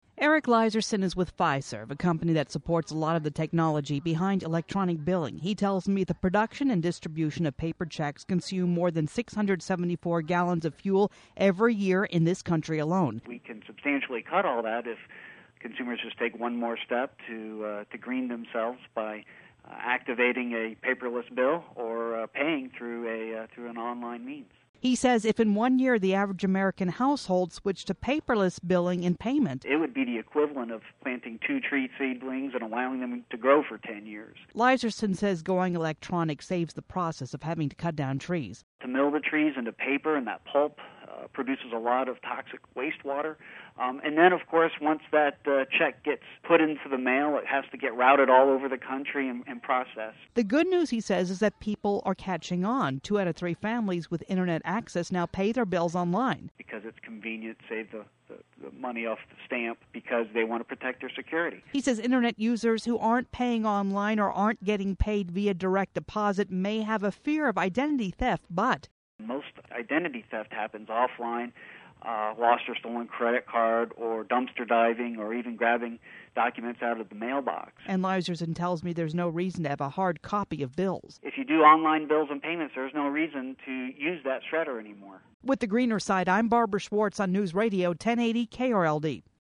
Sample Media Interviews